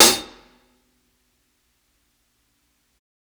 60s_HH_LOUD.wav